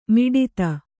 ♪ miḍita